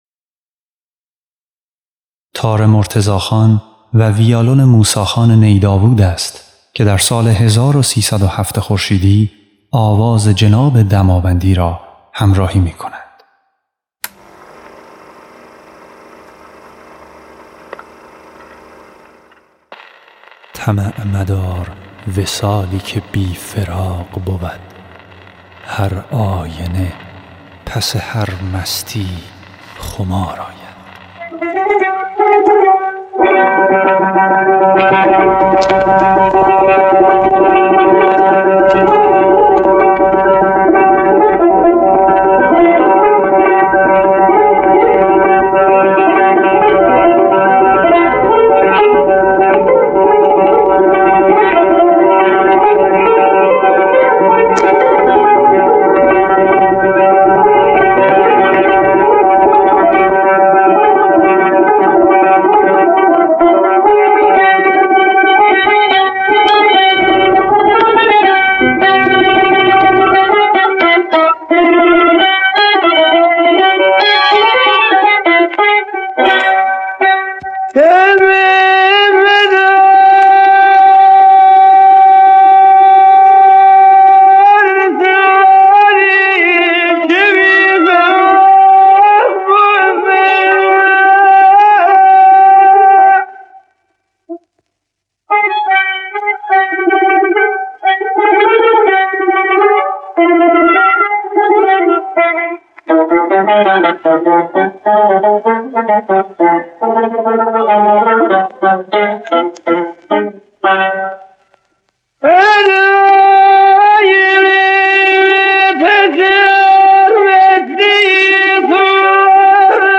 نوازنده تار
موسی نی داوود نوازنده ویالون
نوازنده ویولن
ضبط شده در سال 1307 خورشیدی